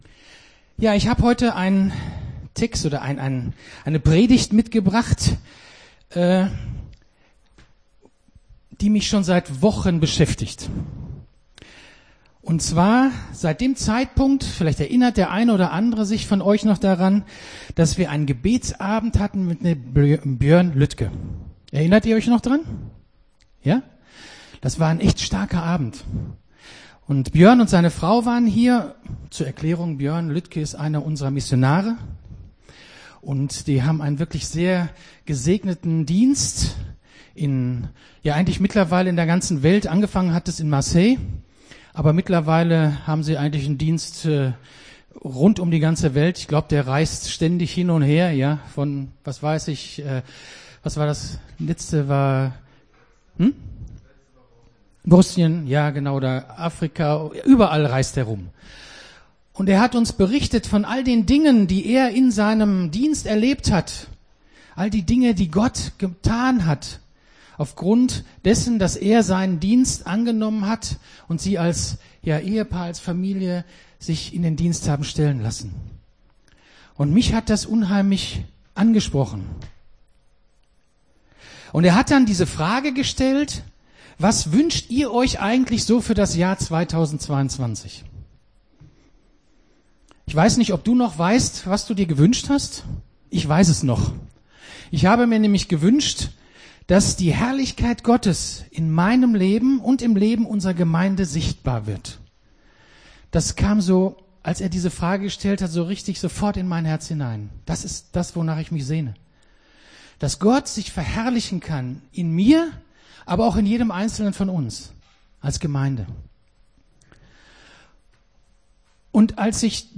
Gottesdienst 03.04.22 - FCG Hagen